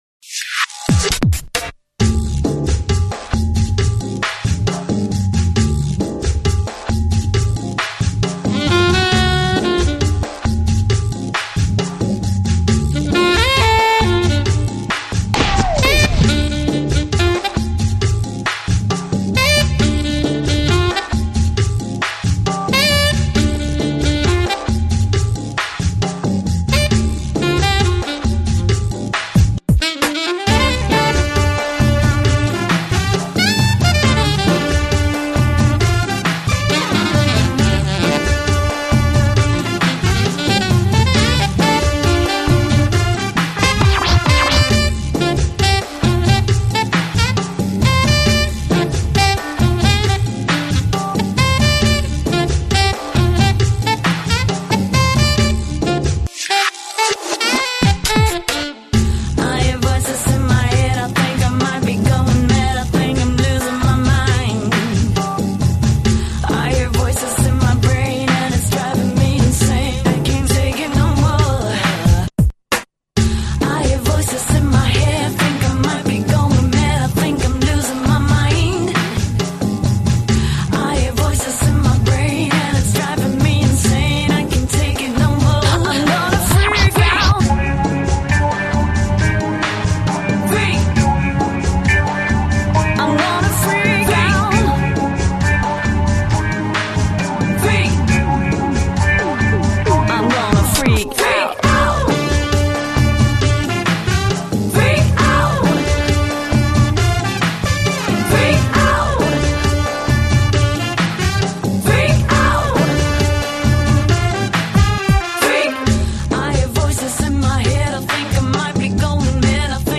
Category Rock/Pop, Jazz, Jazz Instrument, Saxophone